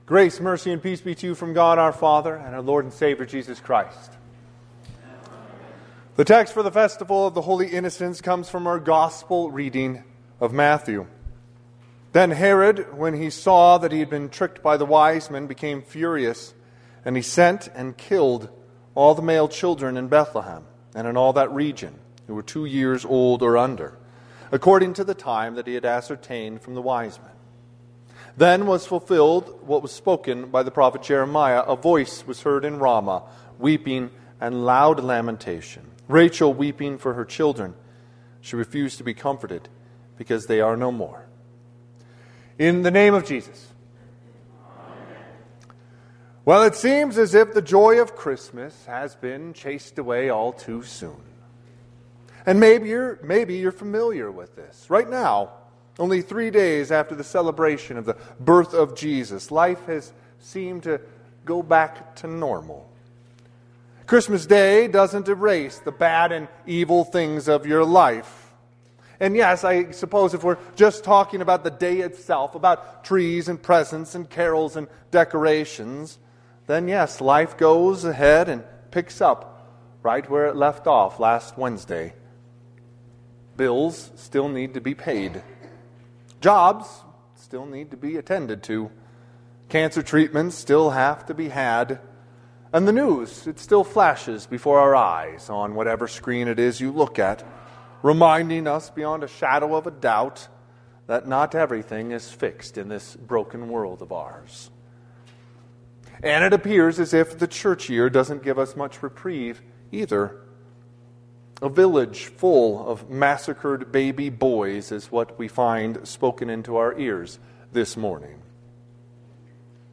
Sermon - 12/28/2025 - Wheat Ridge Evangelical Lutheran Church, Wheat Ridge, Colorado